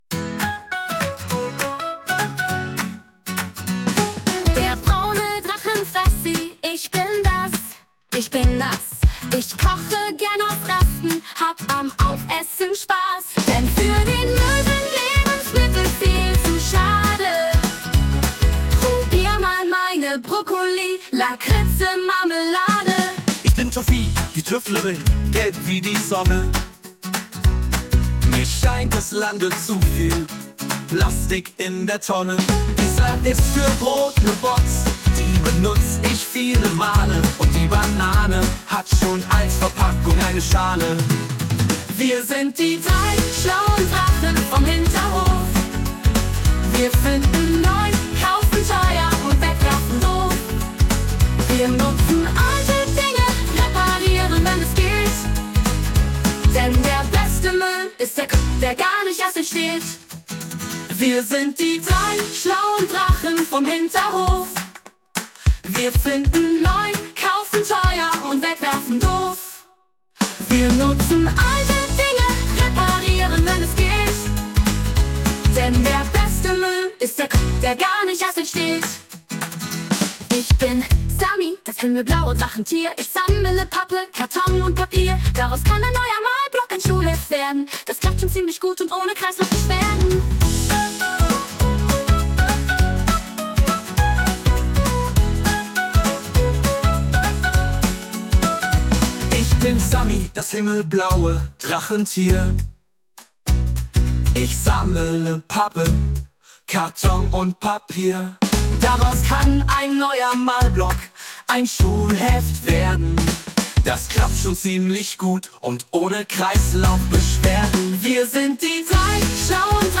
Der Drachen Song Die Geschichte der drei Drachen lässt sich auch tanzen und singen.